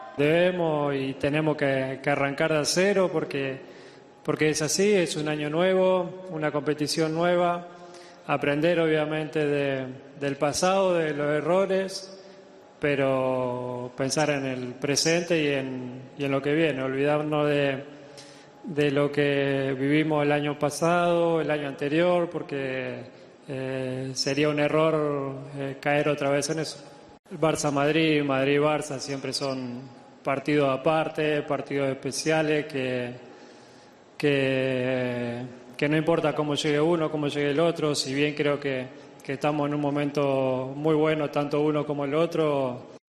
Messi en el acto de presentación de sus nuevas botas.